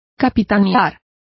Complete with pronunciation of the translation of captained.